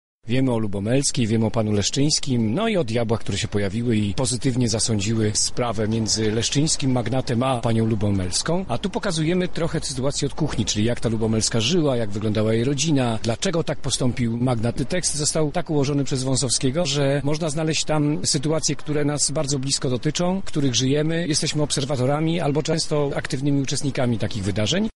• mówi aktor